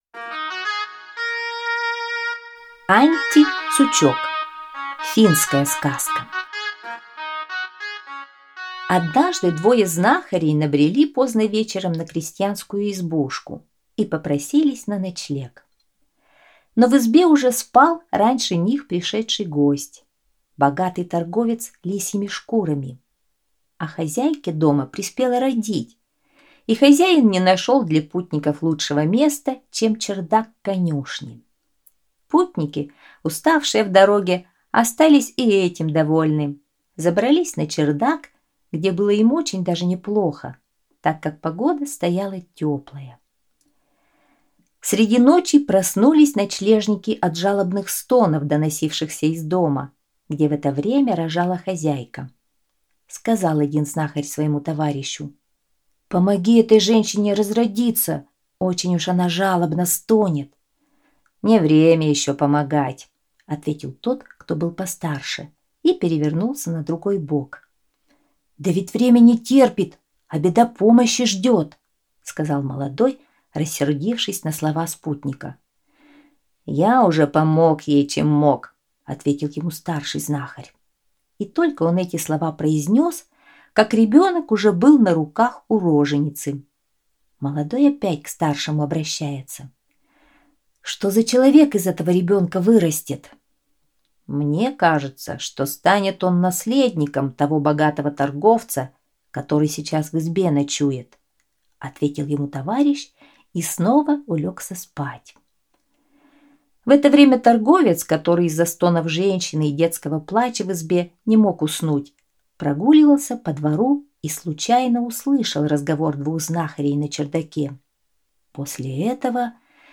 Антти-Сучок - финская аудиосказка - слушать скачать